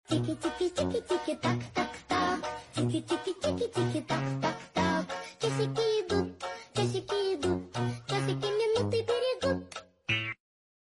clocks part 1 and 2 sound effects free download